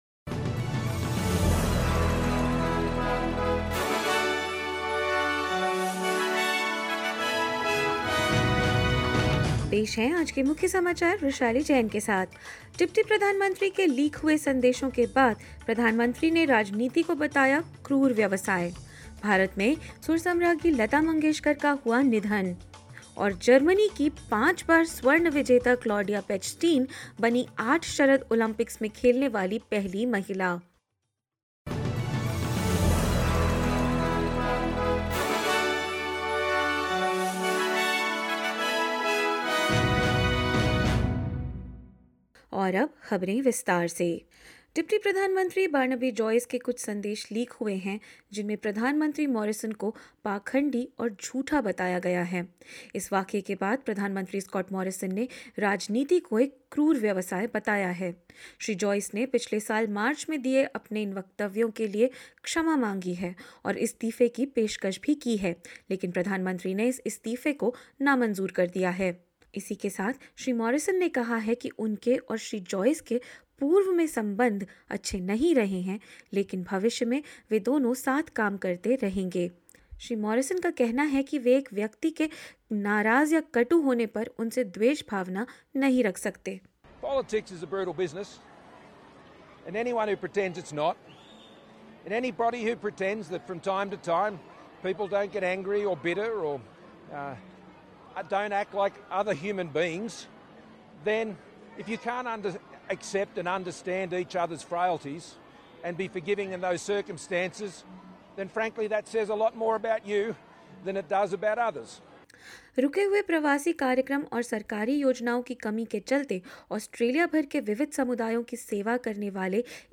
hindi_news_0602.mp3